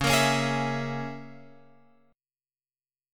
C#m6 chord